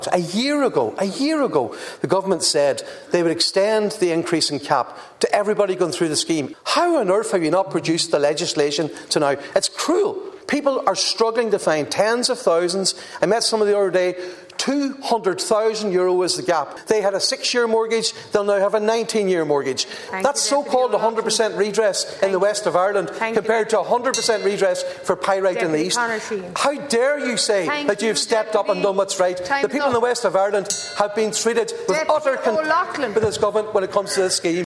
The Dail has been told the failure to pass legislation extending the increase in the cap for defective block homeowners to those already going through the scheme is cruel.
Deputy Padraig MacLochlainn told TDs the reality on the ground is that people are facing huge gaps between the actual cost of the remediation and what’s available through the scheme.